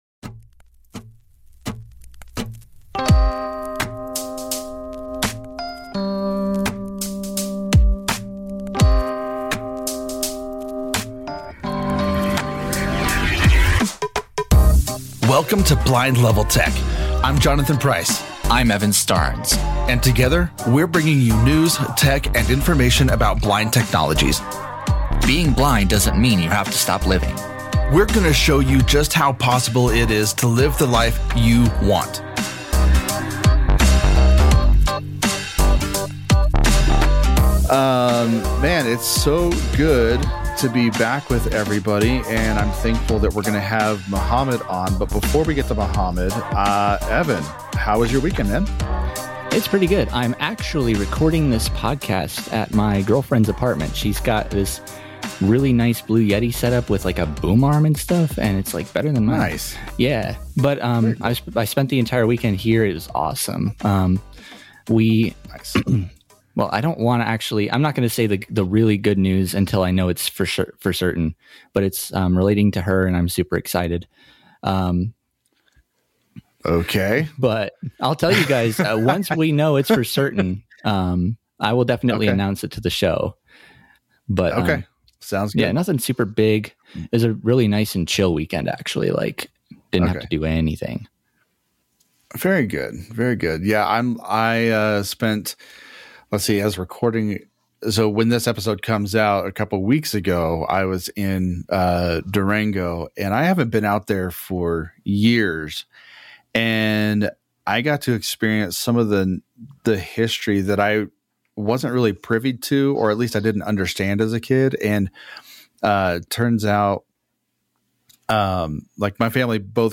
The hosts then finish off with their unique 'Sandwich of the Week' segment and some closing announcements. Tune in to stay updated with the evolutions in blind technologies.